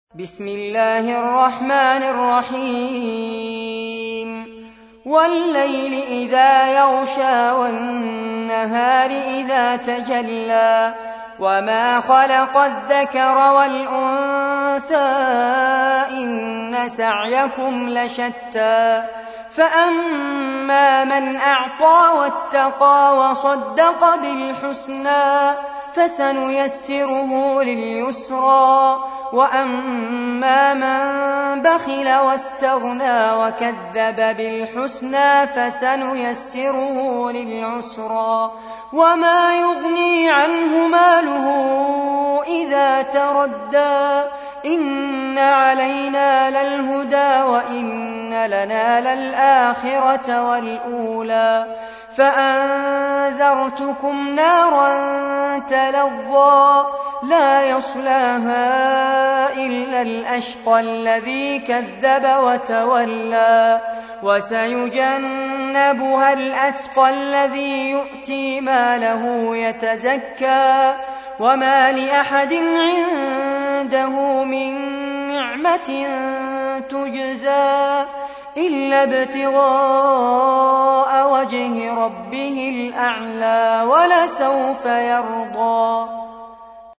سورة الليل / القارئ فارس عباد / القرآن الكريم / موقع يا حسين